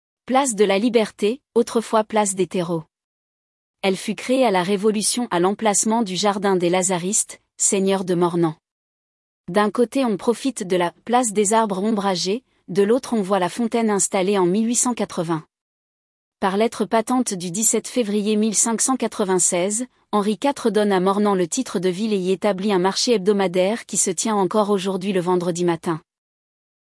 audio guide place de la liberté